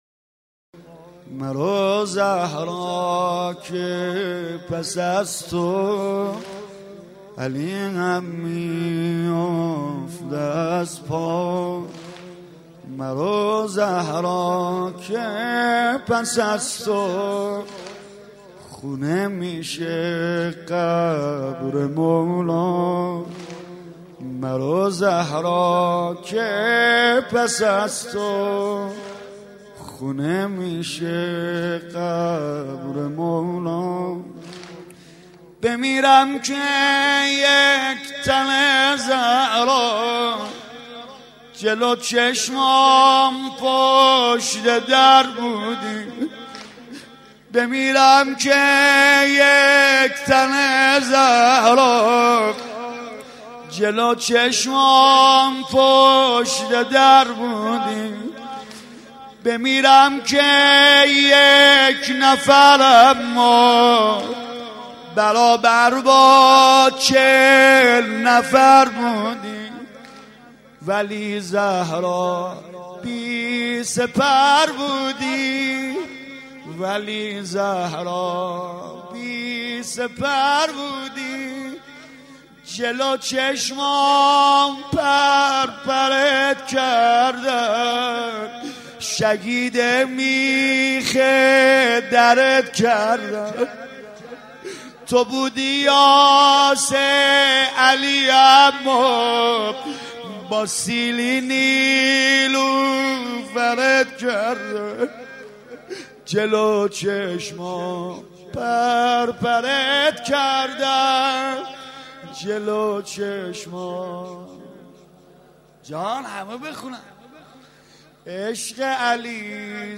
گلچین فاطمیه سید رضا نریمانی